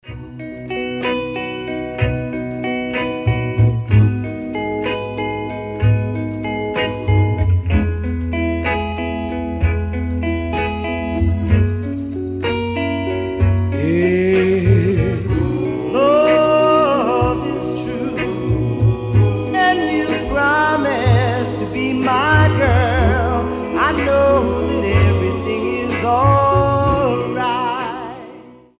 • Качество: 320, Stereo
красивая мелодия
медленные
блюз
Чудесная песня в стиле doo-wop и blues